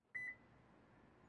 汽车 " 汽车锁
描述：这是2013年起亚灵魂锁定门的声音。
标签： 汽车 锁定车
声道立体声